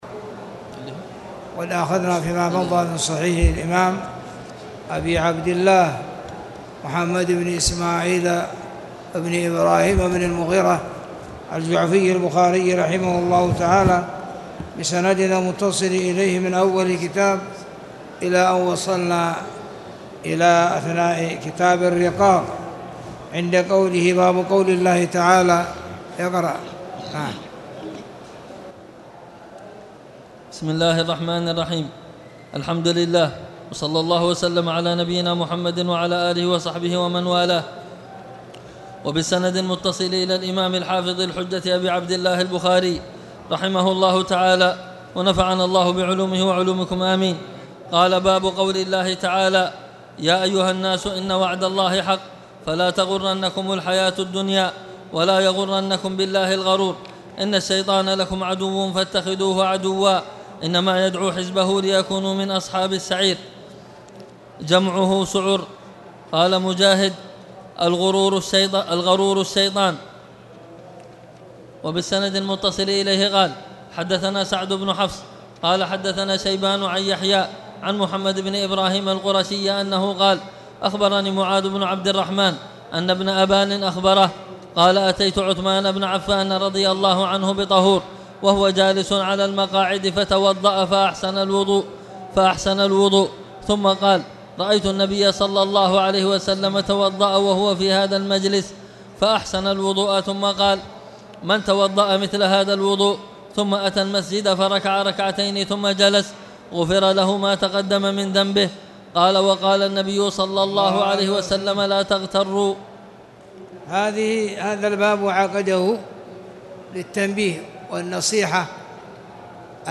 تاريخ النشر ٢ رمضان ١٤٣٨ هـ المكان: المسجد الحرام الشيخ